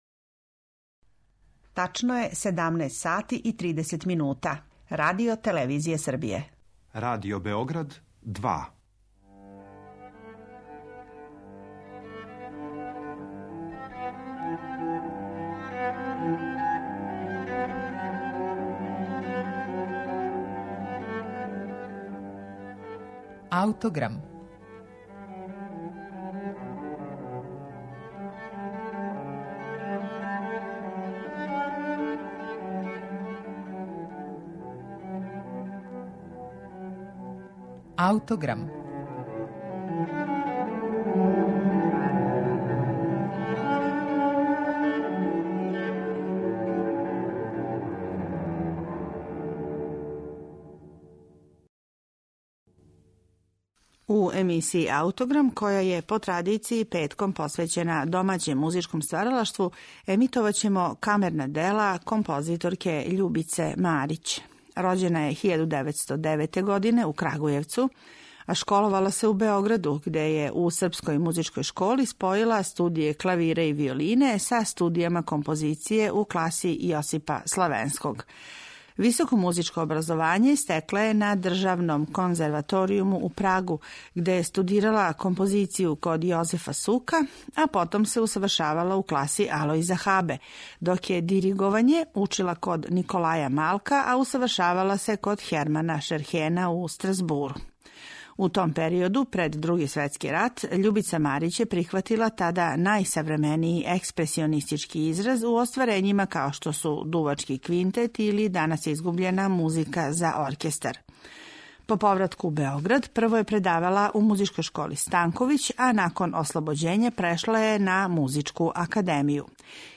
уз виолину и гудаче